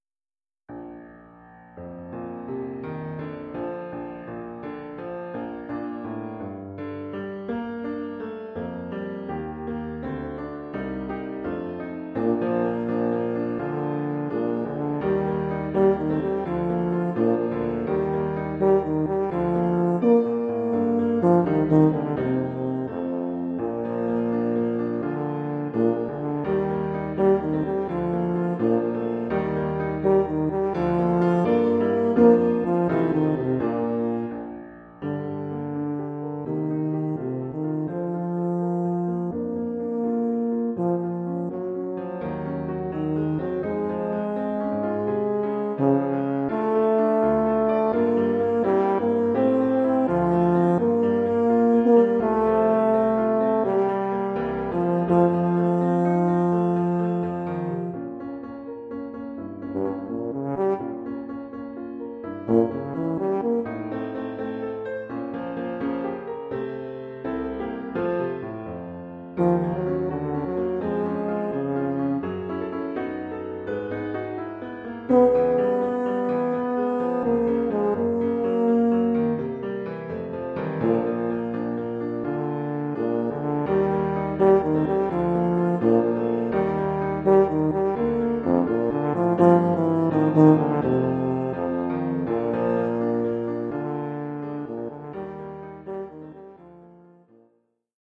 Oeuvre pour saxhorn basse / euphonium /
tuba et piano.